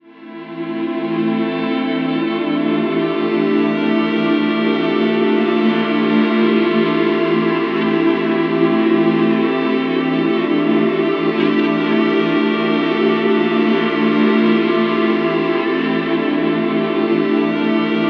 WEEPING 3 -L.wav